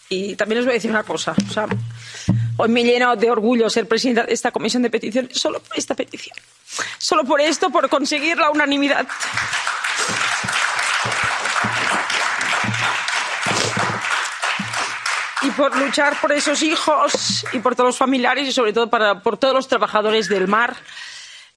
"Hoy me llena de orgullo ser presidenta de esta comisión de peticiones solo por esta petición. Solo por esto, por conseguir la unanimidad y por luchar por esos hijos y familiares y todos los trabajadores del mar", afirmó, con la voz entrecortada por la emoción, la española Dolors Monserrat (PP), en su intervención en la comisión del Parlamento Europeo que escuchó las demandas de las familias de los 21 fallecidos en el naufragio del Villa de Pitanxo en Terranova.